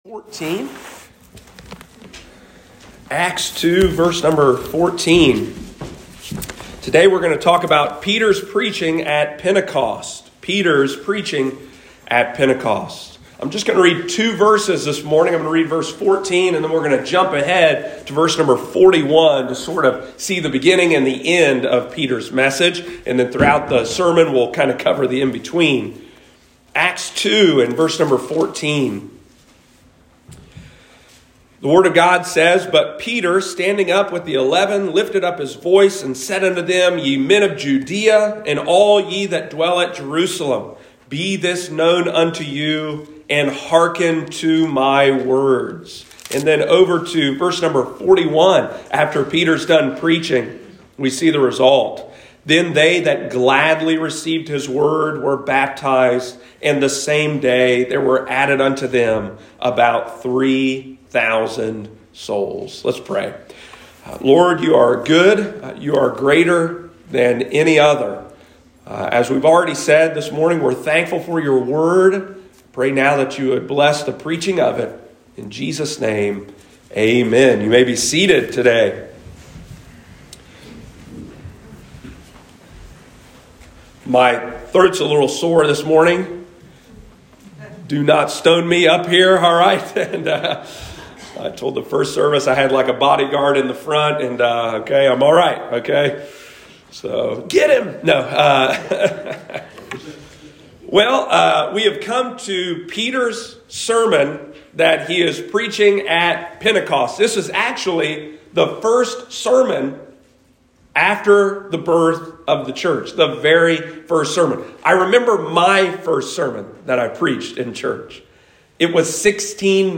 What did preaching look like in the first century church? Listen to today’s sermon as we continue our series through the book of Acts and study they first sermon of the church.